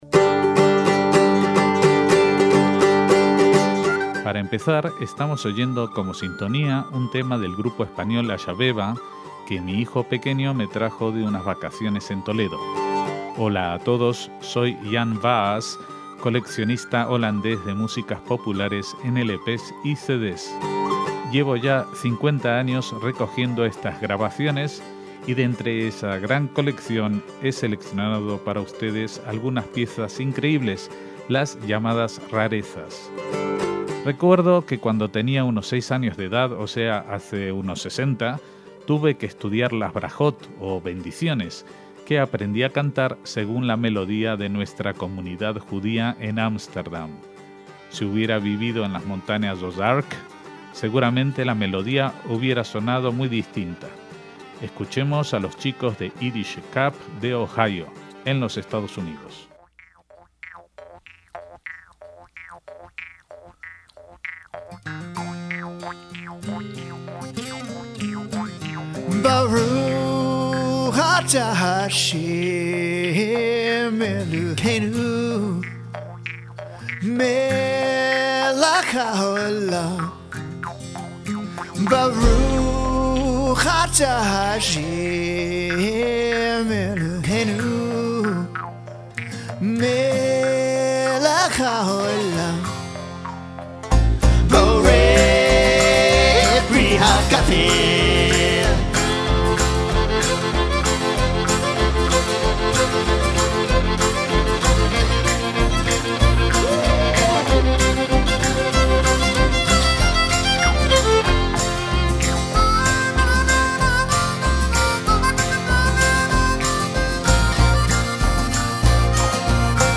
Entre el klezmer y el bluegrass